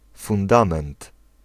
Ääntäminen
Synonyymit assise constitution Ääntäminen France: IPA: [fɔ̃.da.sjɔ̃] Haettu sana löytyi näillä lähdekielillä: ranska Käännös Ääninäyte 1. podstawa {f} 2. fundament {m} 3. baza {f} Suku: f .